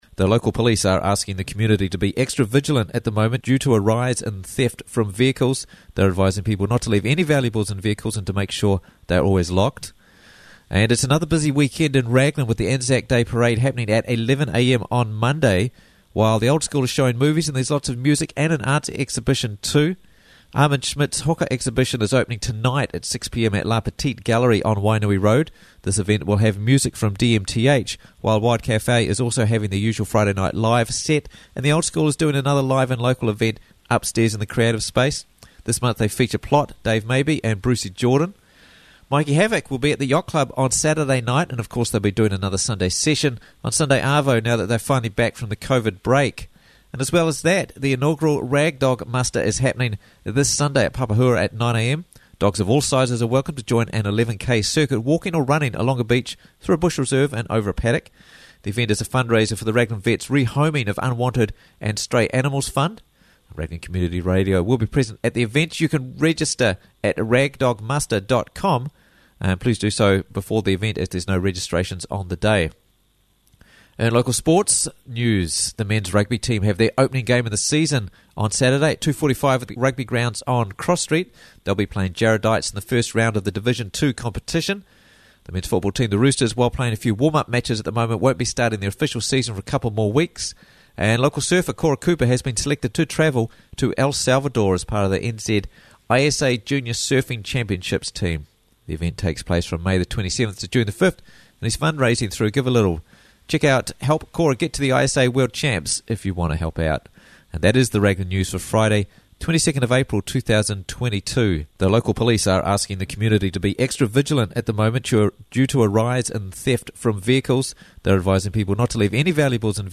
Raglan News Bulletin